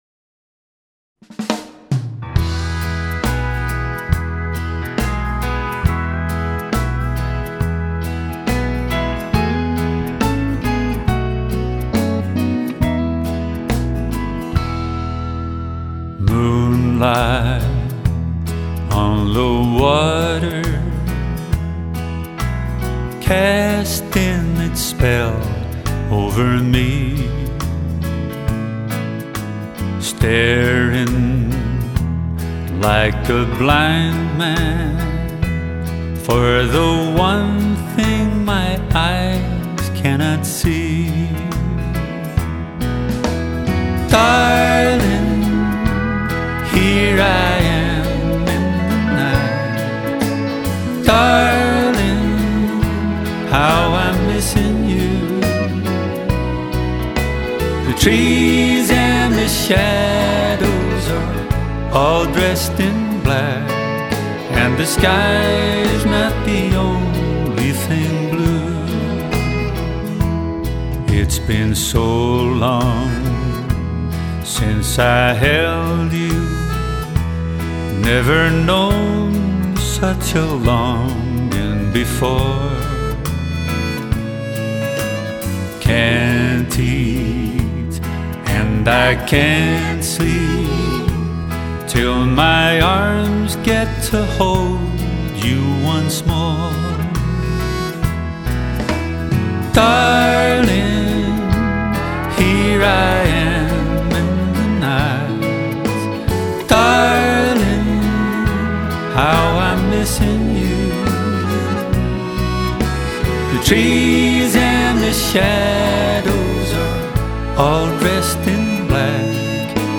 以一把清新的醇厚的嗓子献唱出十七首创作作品